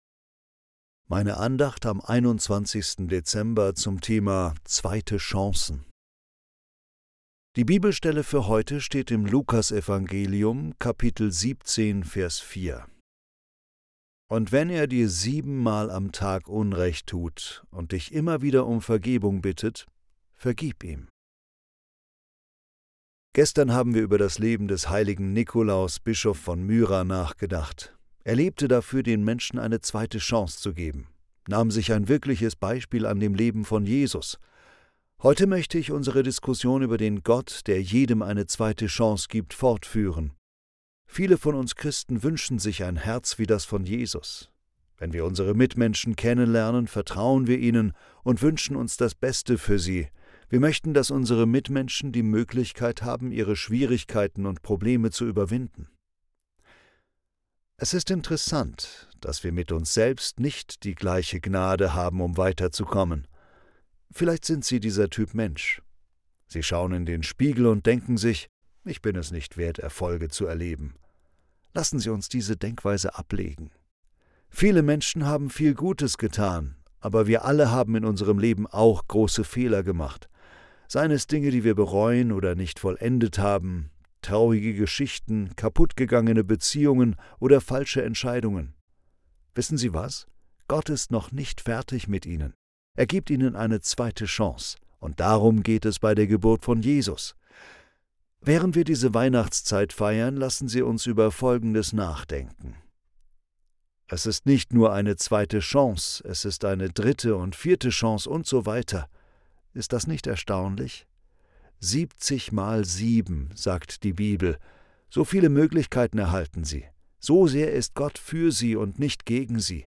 Andacht zum 21. Dezember